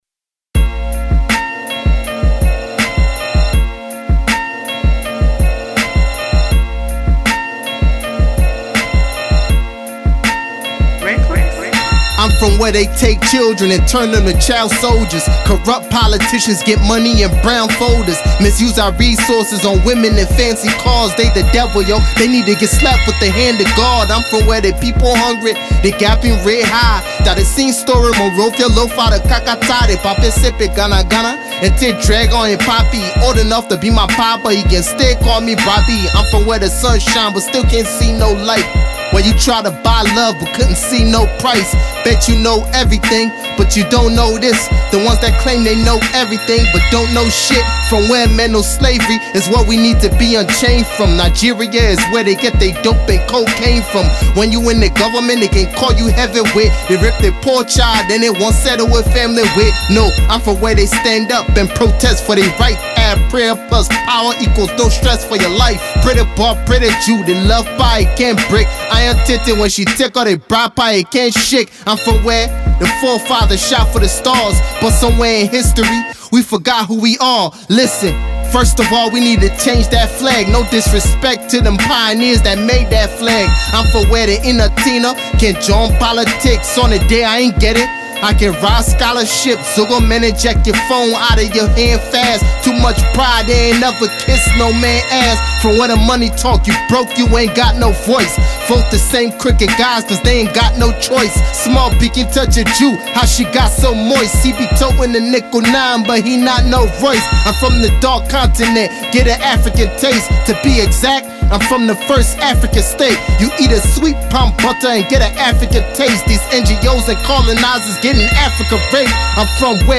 / Hip-Hop, Hip-Co, Liberian Music / By